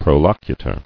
[pro·loc·u·tor]